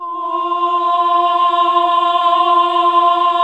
AAH F2 -R.wav